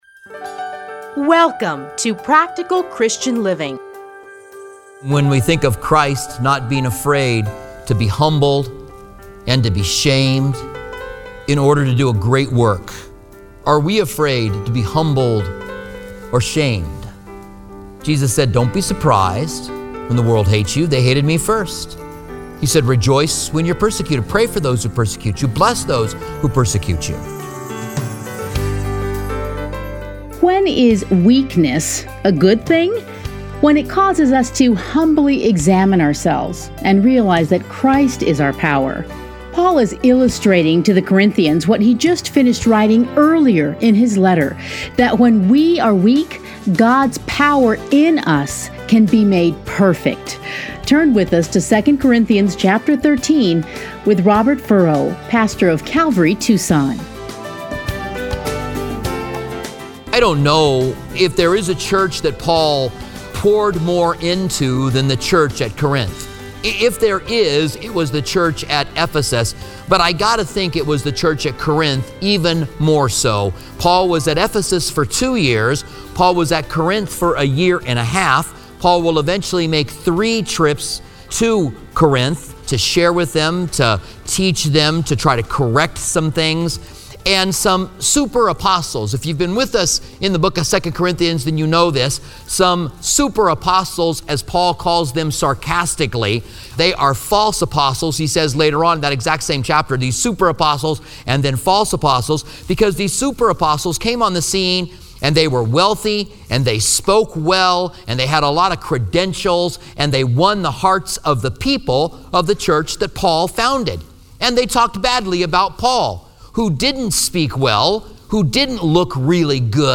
Listen here to a teaching from 2 Corinthians.